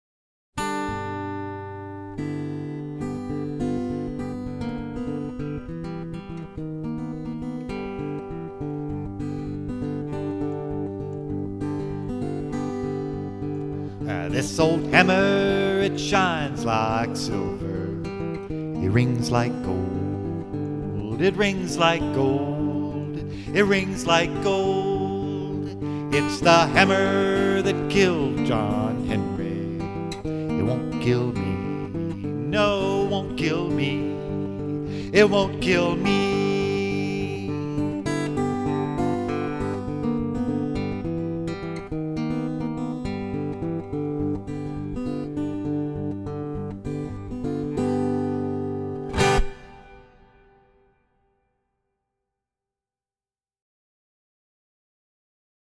American folk song